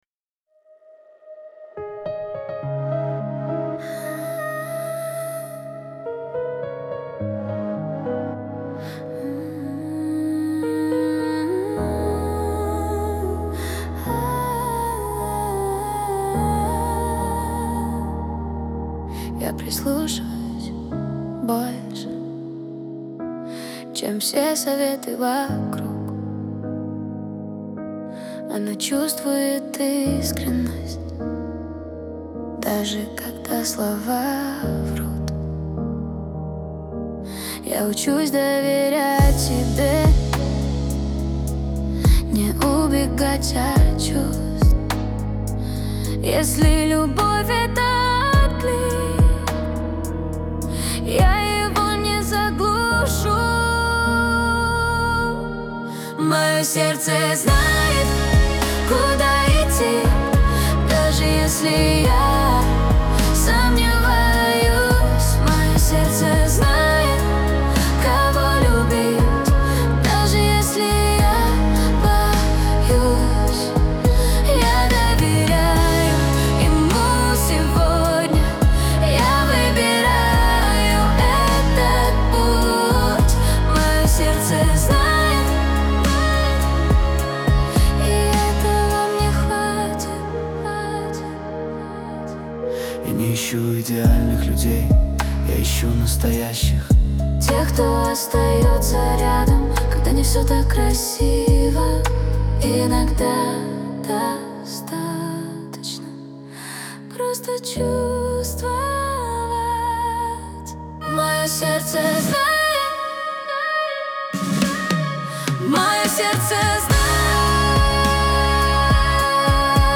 Русская Поп-Музыка